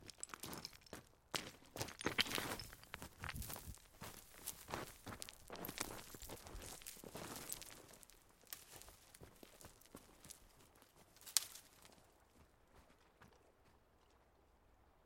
地、水、火、风竞赛 " 沼泽沙砾上的脚步声(清洁)
描述：在科罗拉多州的山区，穿着一双钓鱼用的涉水鞋，在松散的湿润的砾石上行走的片段，用猎枪式话筒录制而成
Tag: 地球 足迹 碎石 步骤 行走